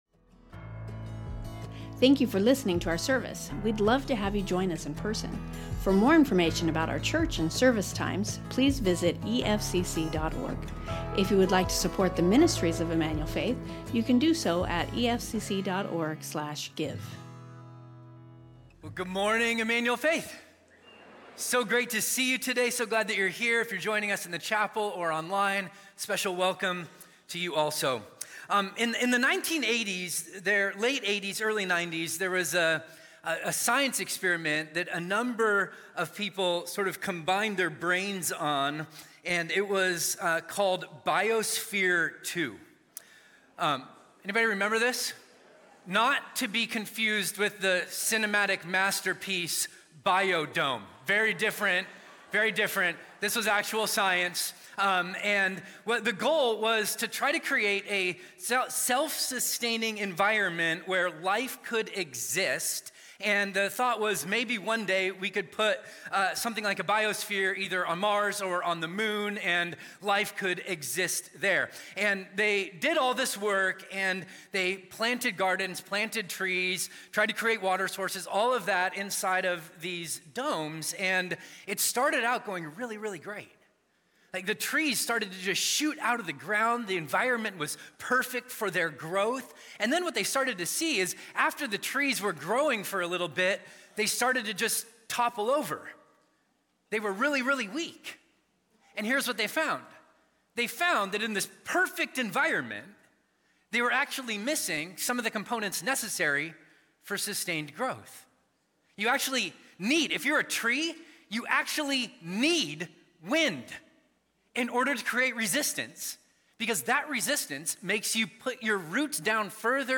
Emmanuel Faith Sermon Podcast Faith and Famine | Genesis 12:9-20 Apr 20 2026 | 00:43:28 1x 00:00 / 00:43:28 Subscribe Share Spotify Amazon Music RSS Feed Share Link Embed